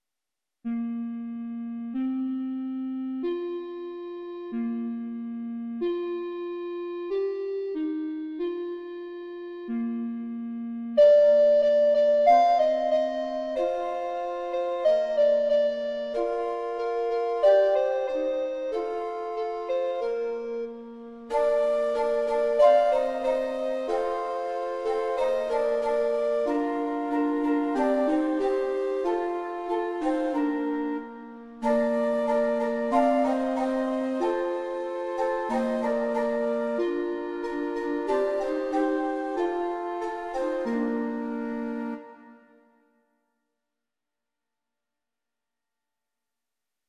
Canon, 3 stemmen, Duits en Nederlands
driestemmig